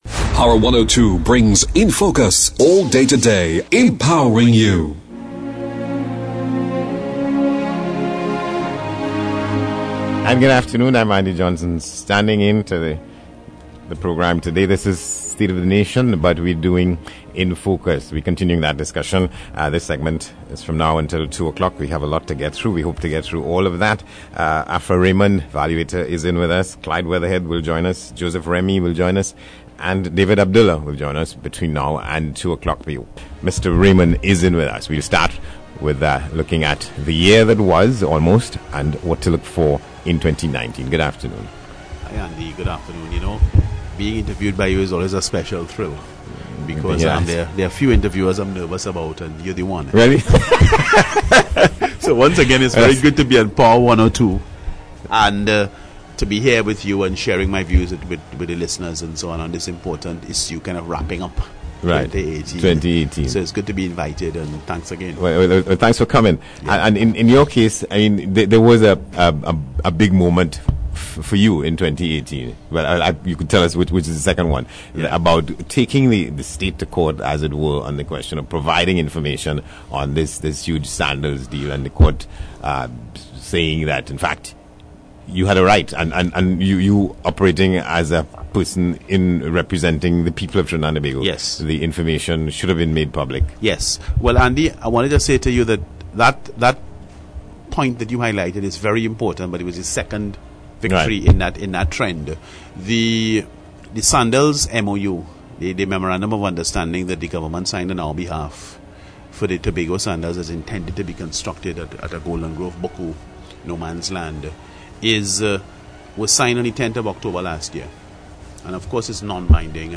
Radio station Power 102.1 FM hosted an all day retrospective discussion on the burning issues of 2018 on Wednesday 19th December 2018.